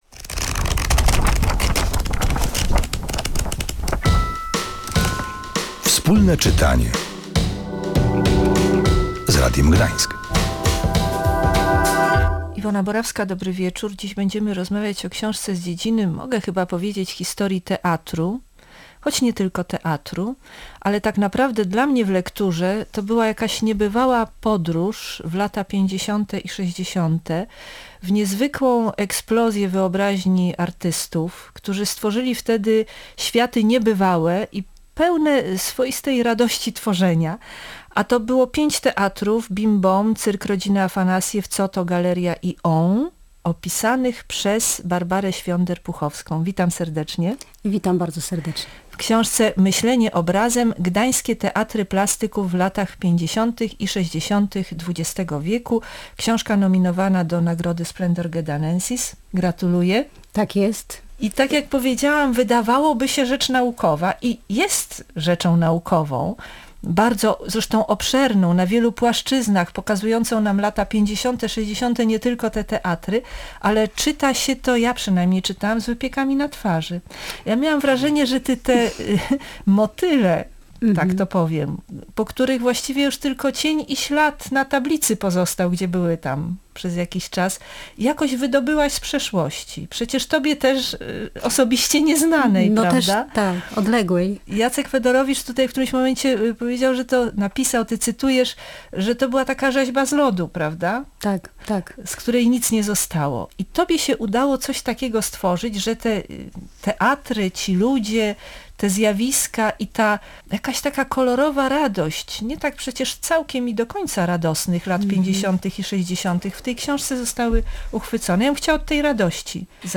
W audycji rozmowa